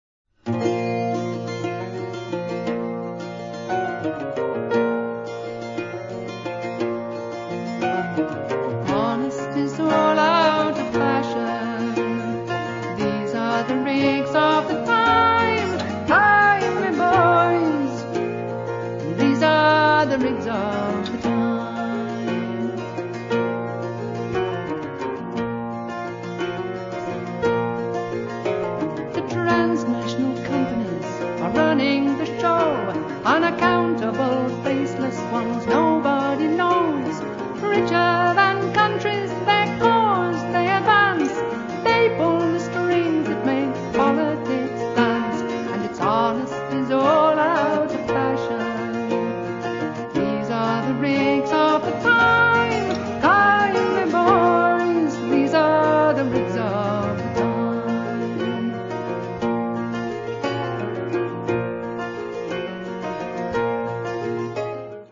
First part, 1:05 sec, mono, 22 Khz, file size: 255 Kb.